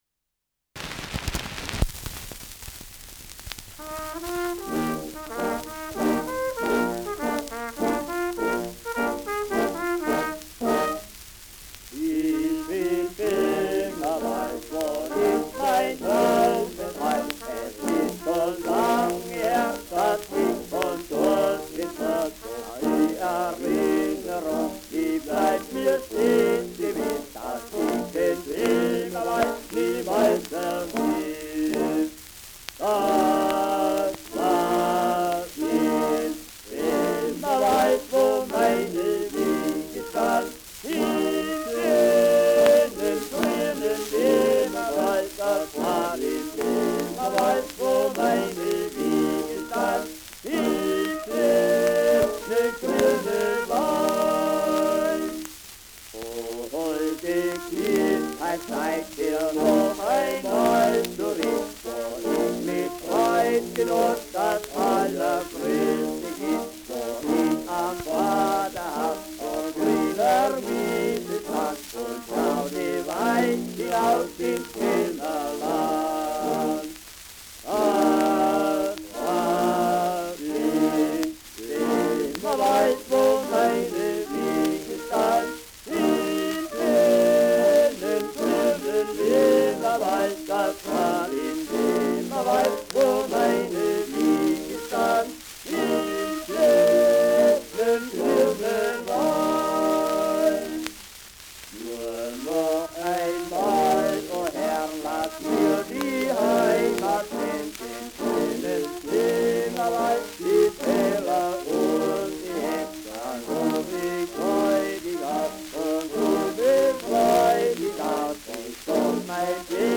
Schellackplatte
präsentes Rauschen : leichtes Knistern